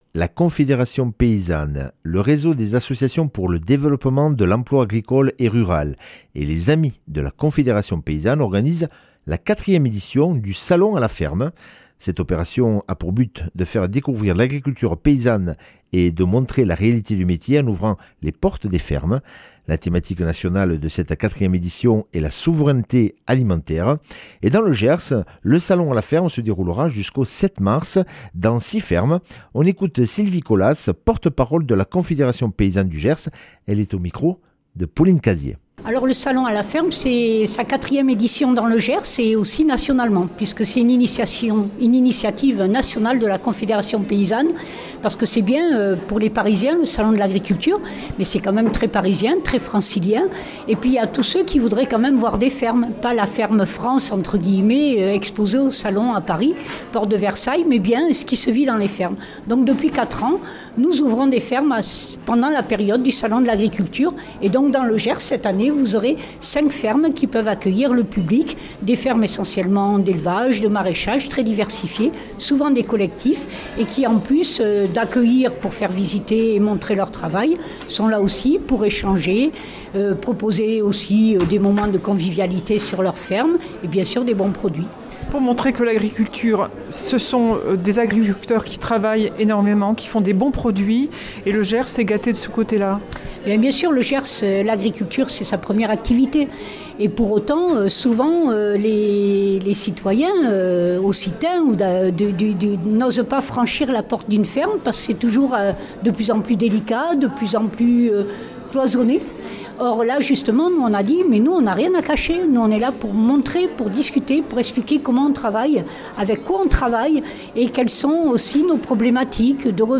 | Interview et reportage